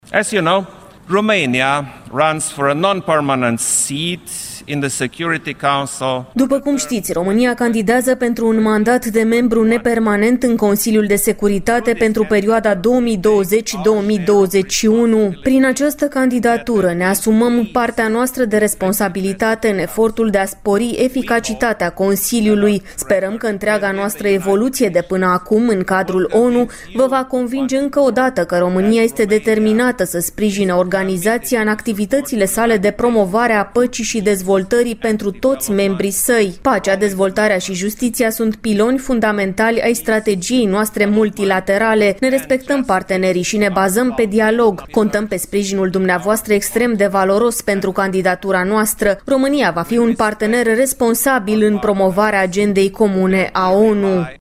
Preşedintele Klaus Iohannis a pledat de la tribuna ONU pentru alegerea României ca membru nepermanent în Consiliul de Securitate. În discursul rostit în cadrul Adunării Generale a Naţiunilor Unite, preşedintele a spus că pacea, dezvoltarea şi justiţia reprezintă baza strategiei multilaterale a ţării.